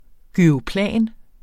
Udtale [ gyʁoˈplæˀn ]